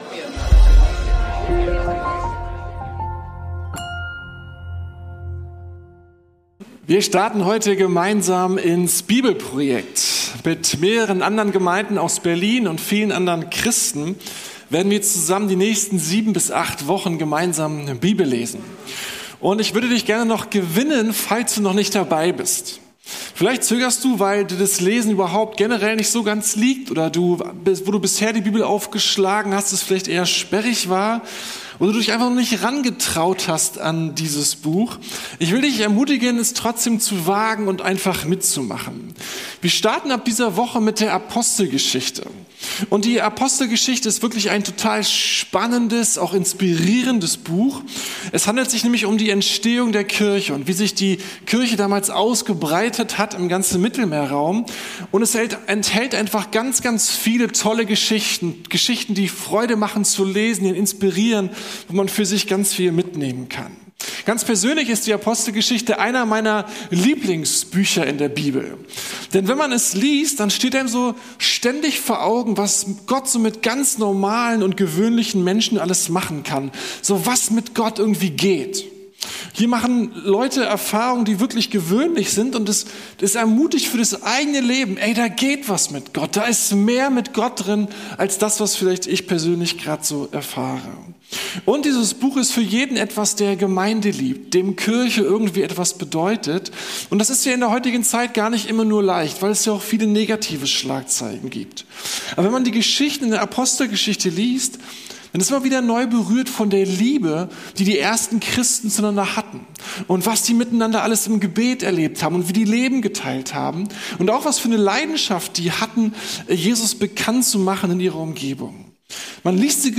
Gottes Mission - Gottes Power ~ Predigten der LUKAS GEMEINDE Podcast